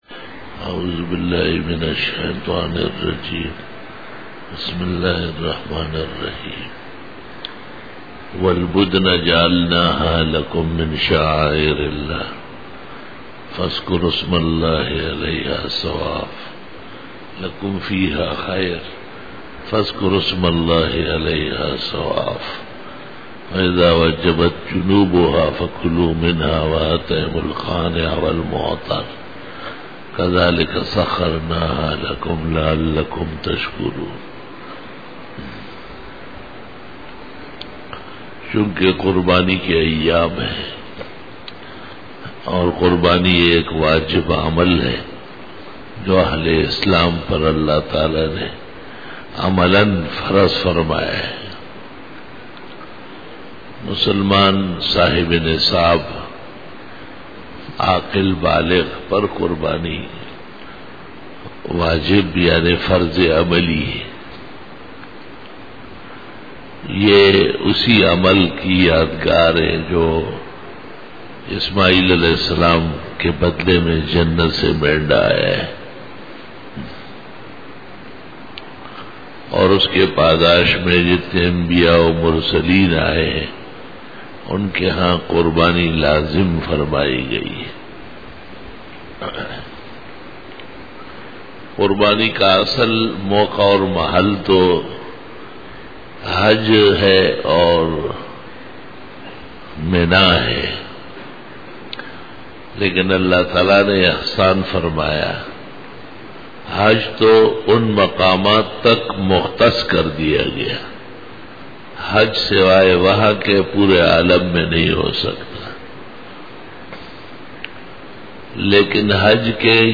40_BAYAN E JUMA TUL MUBARAK 10-OCTOBER-2013